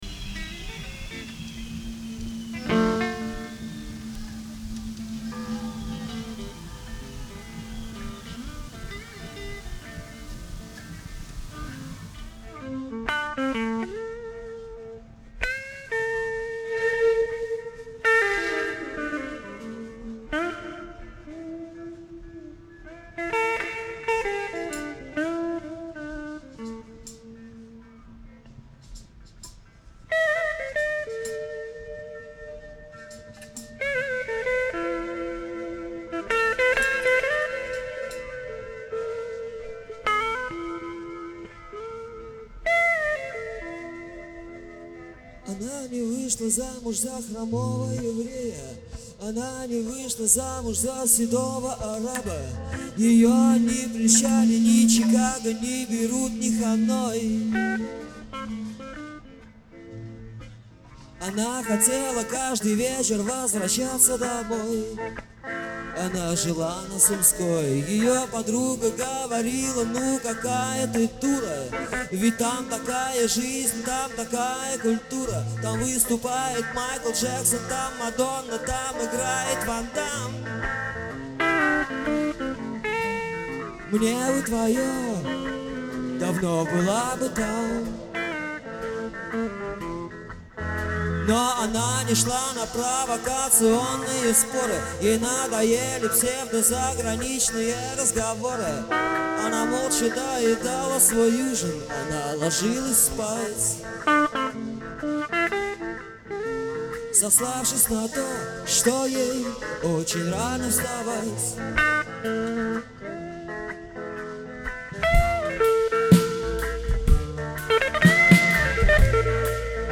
вокал, ак.гитара
эл.гитара, бэк-вокал
ударные
бас, бэк-вокал